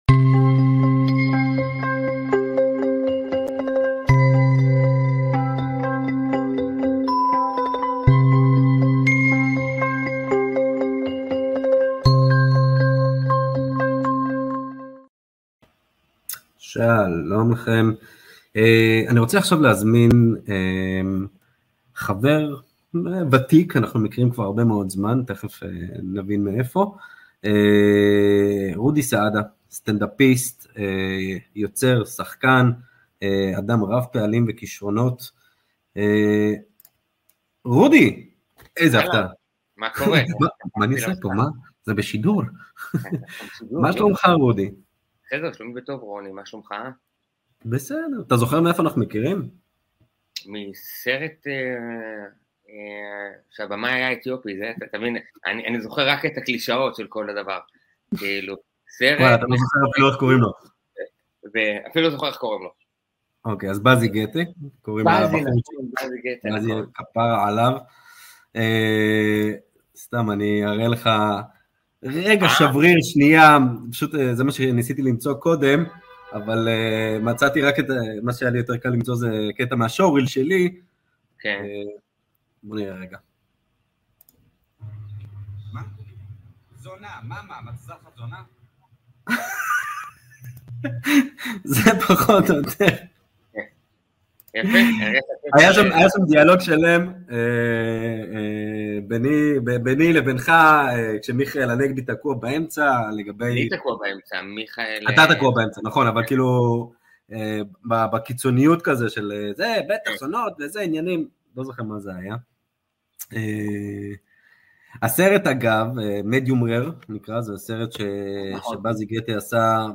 לשיחה על סטנדאפ, חוויות משנות חיים שמשפיעות על היצירה, היצירה עצמה וההתמודדות עם החיים בימינו.